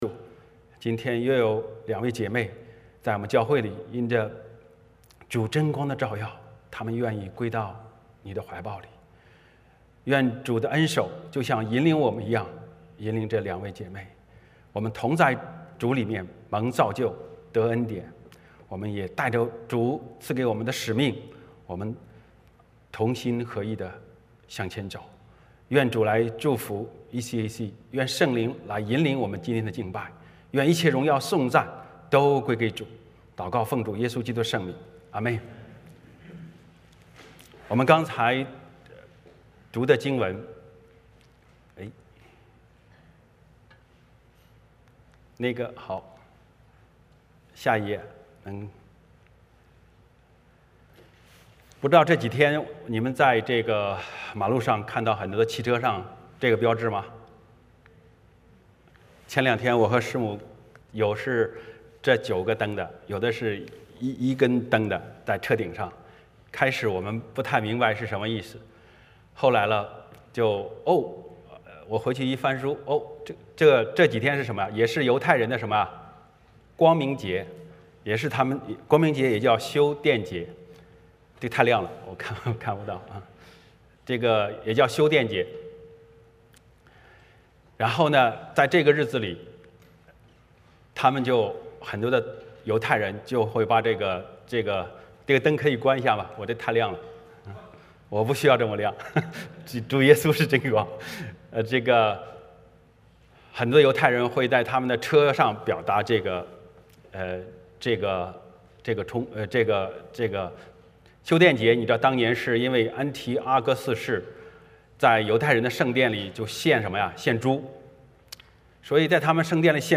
欢迎大家加入我们的敬拜。